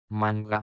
La -g- gutturale (come in "guanto"), usata nei gruppi -ga, go, gu- ha due suoni, uno "esplosivo" quando è dinnanzi alla parola o dopo la -n- (viene stretta la bocca per scandire il suono):